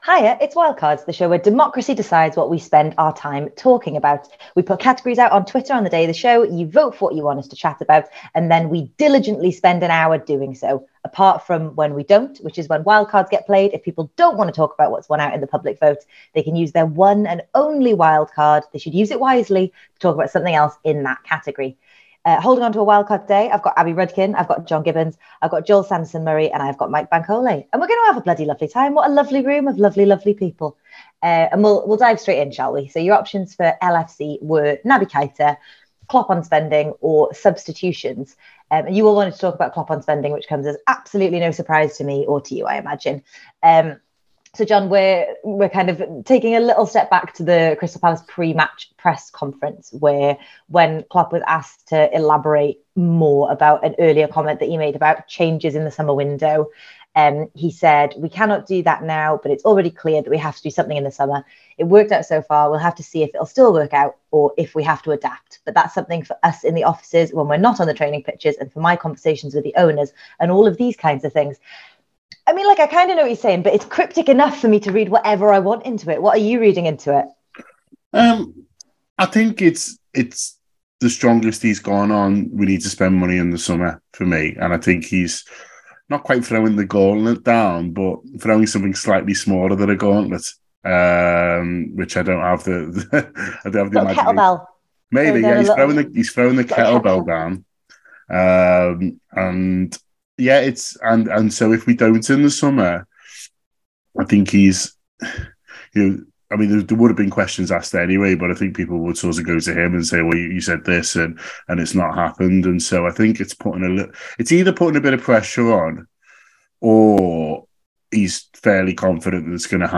The Anfield Wrap’s Wildcards panel discuss Jürgen Klopp’s quotes on Liverpool’s spending, Graham Potter, Jake Paul v Tommy Fury and more.